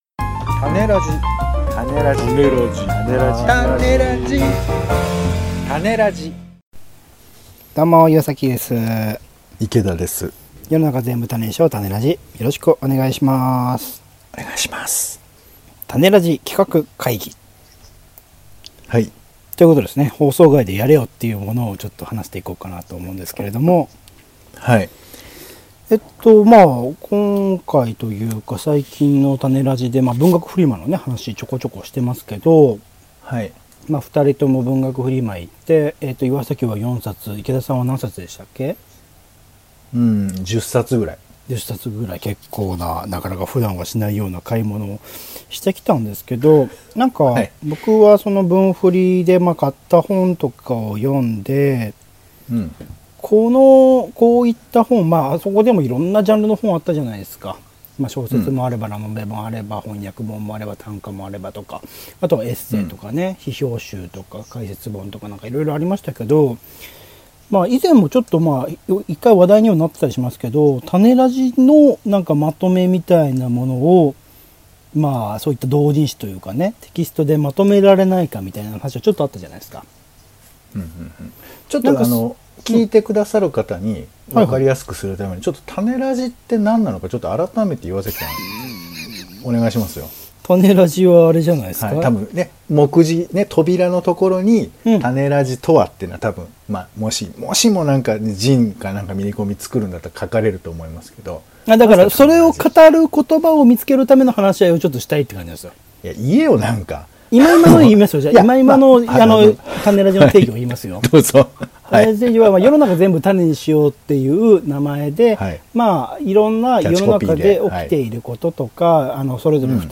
◆タネラジとは イベント運営や編集などをやっている２人のポッドキャストトーク番組。